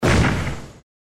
Bomb_3.mp3